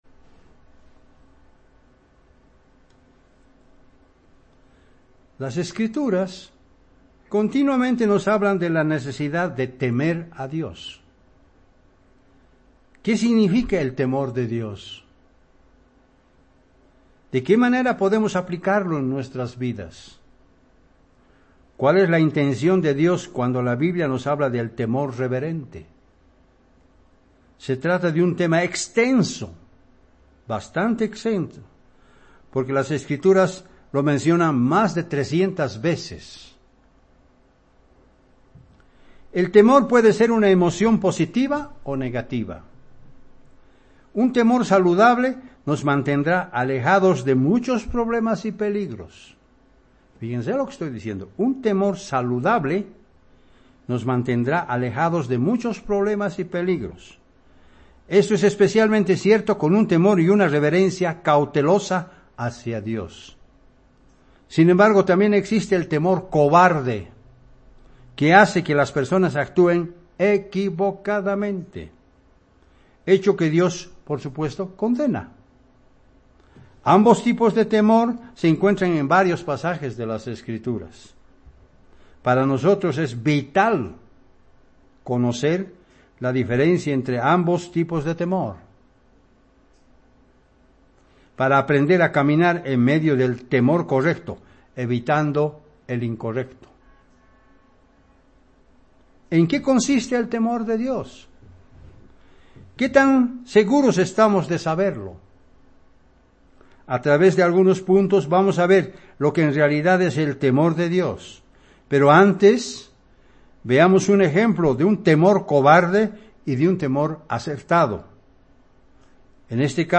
Given in La Paz